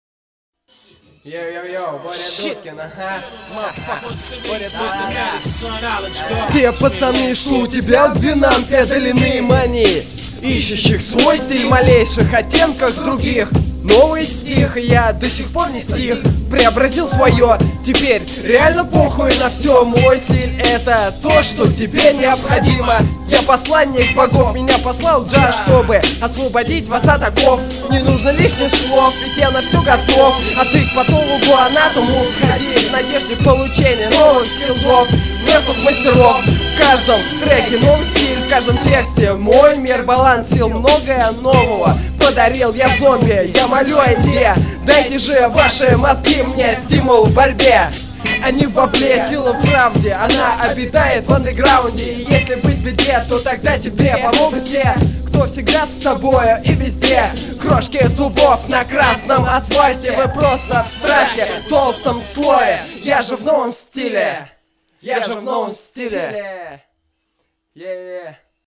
Рэп (46679)